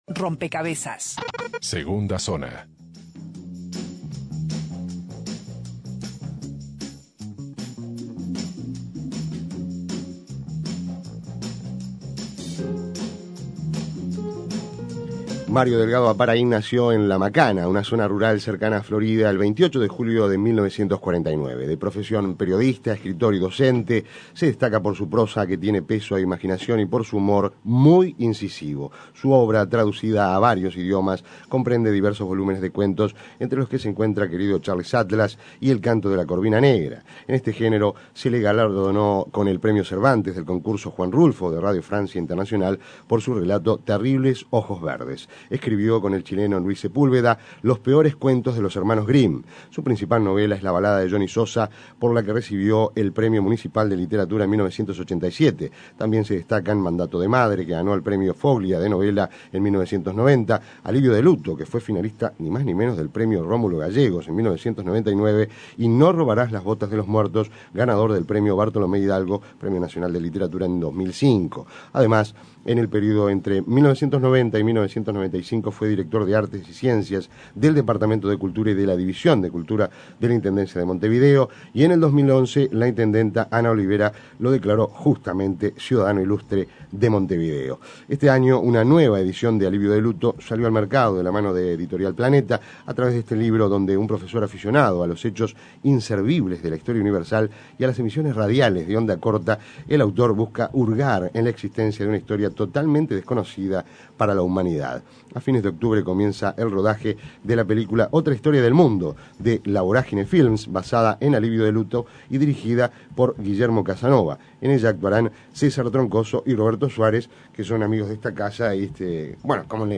Con su gran sentido del humor el escritor recordó su trabajo como cronista policial en una entrevista sin desperdicio.